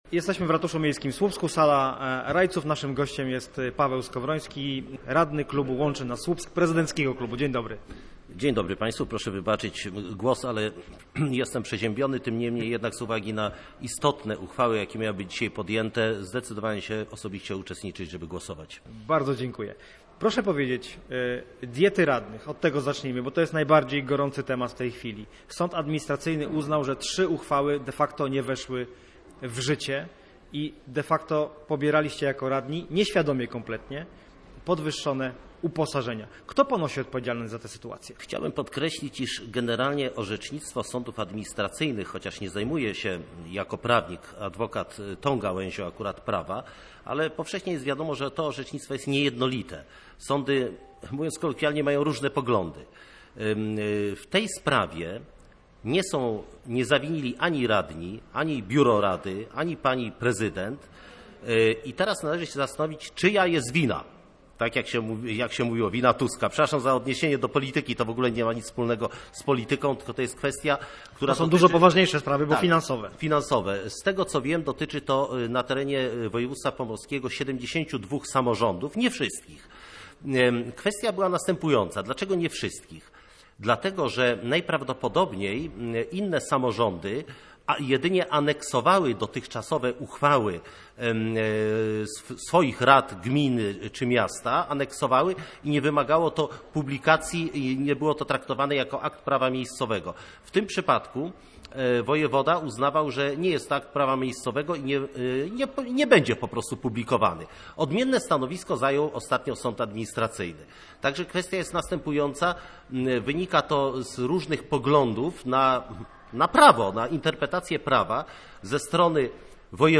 Paweł Skowroński, radny i prawnik tłumaczy, dlaczego Rada Miejska w Słupsku przyjęła uchwałę, która działa wstecz.
Skowronski_gosc_OK.mp3